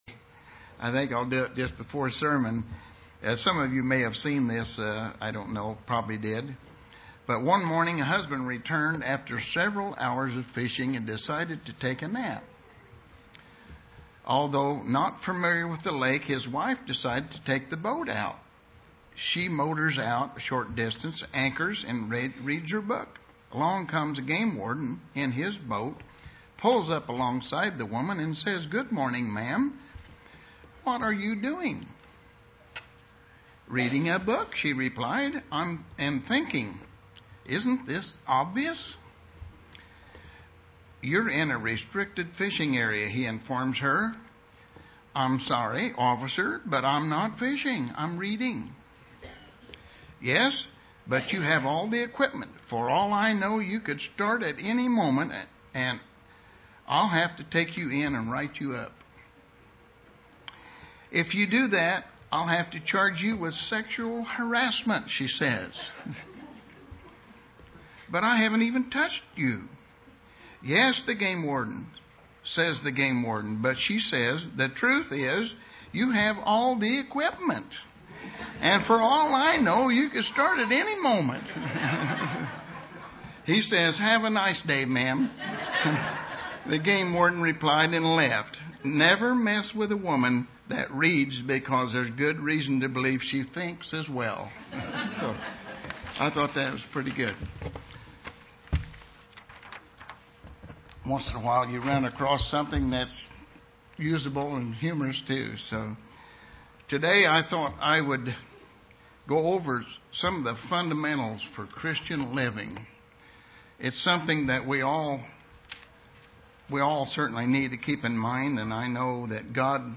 Given in Terre Haute, IN
UCG Sermon Studying the bible?